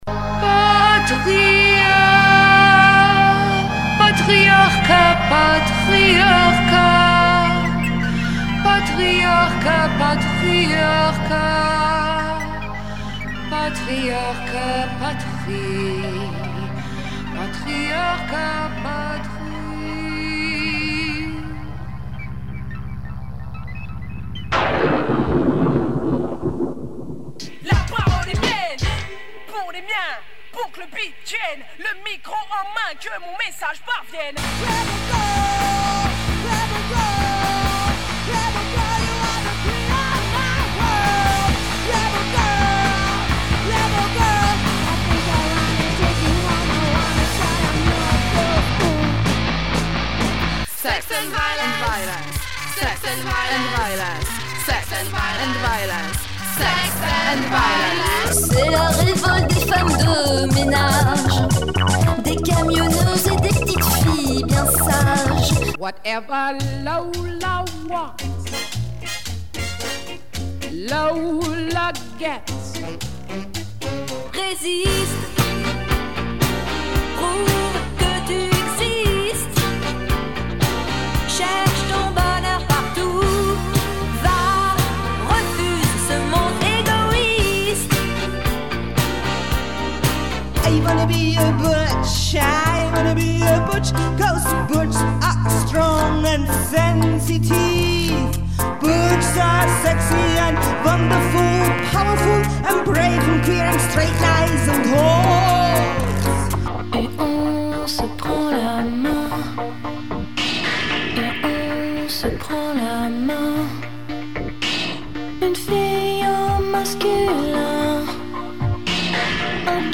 une emission qui est concoctée a partir du son de la nuit de la radio qui a eut lieu à toulouse durant les 2eme rencontre radiorageuses. c’etait une rencontre organisée par le collectif des voy’elles et elles avaient organisée une nuit de la radio sur canal sud.